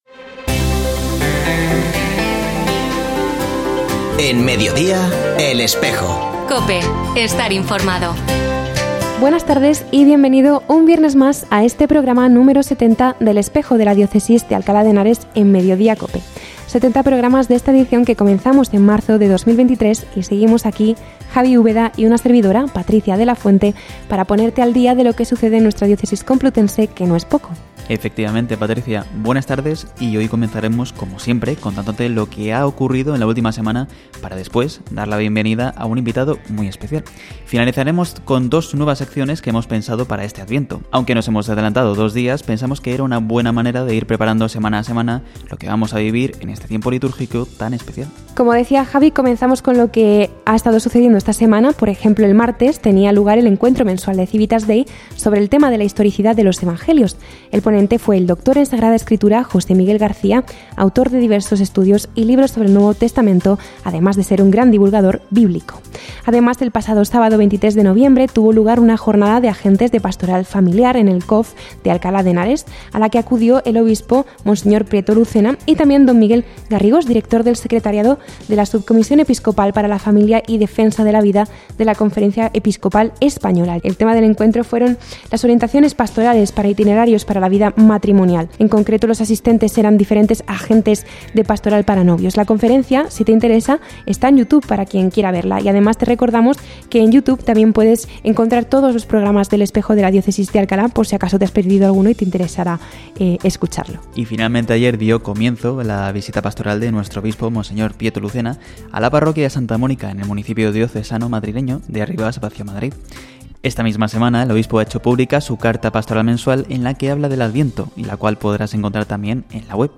Escucha otras entrevistas de El Espejo de la Diócesis de Alcalá
Se ha vuelto a emitir hoy, 29 de noviembre de 2024, en radio COPE. Este espacio de información religiosa de nuestra diócesis puede escucharse en la frecuencia 92.0 FM, todos los viernes de 13.33 a 14 horas.